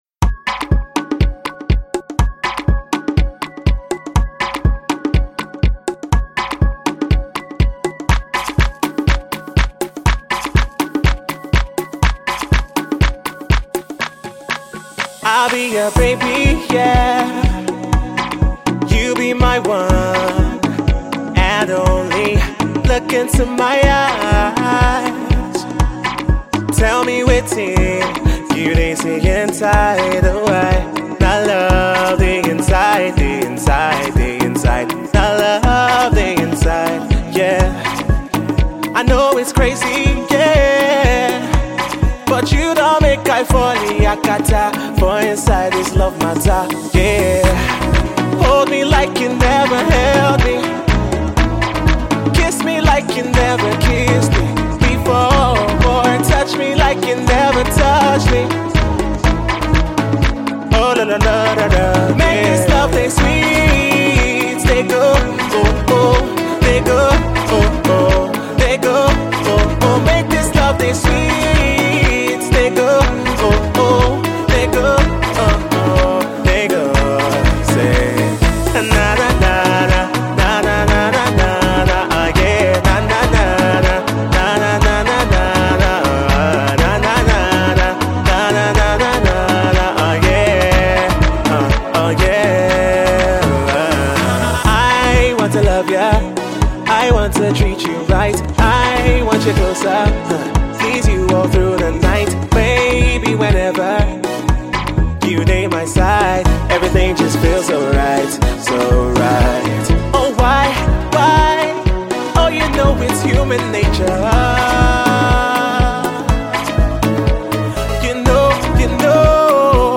love-inspired Afro-Pop
he returns with the bubbly heartthrob anthem